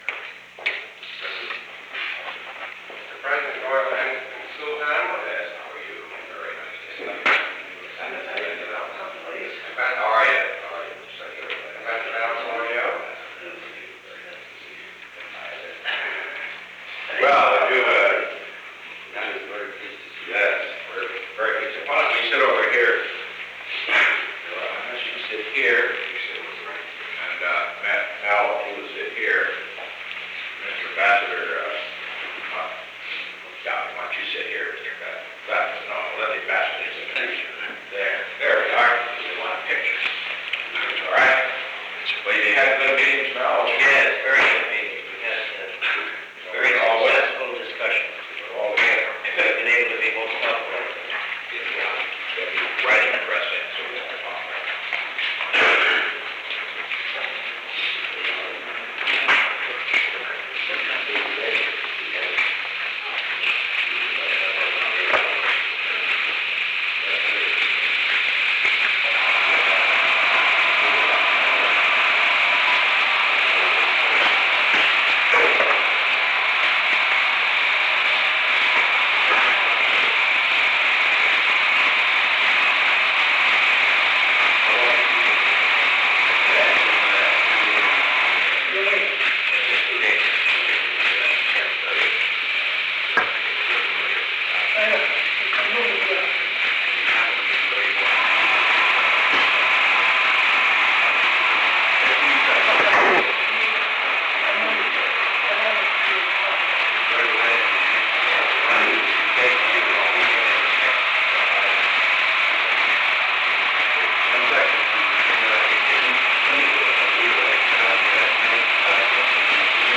3:04-3:45 pm Location: Oval Office The President met with Sultan ibn 'Abd al-'Aziz
Secret White House Tapes